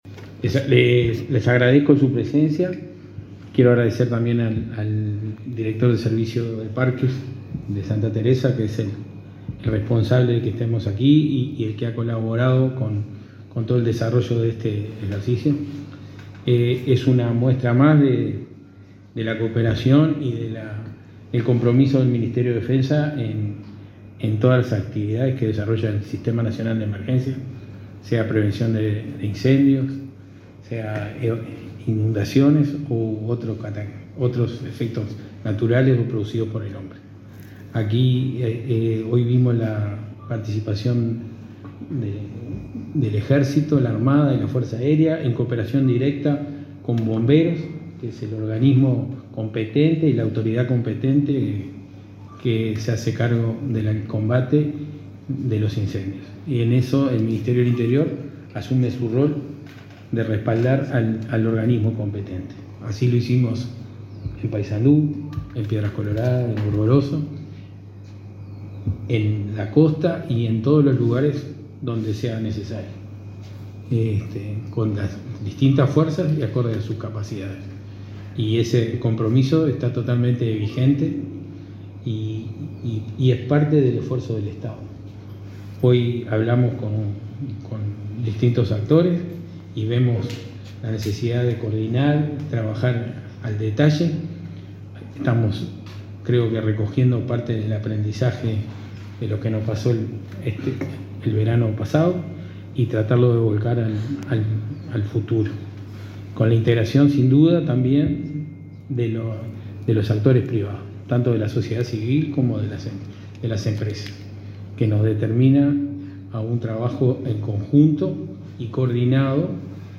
Palabras de autoridades en apertura de temporada de prevención de incendios forestales 2022-2023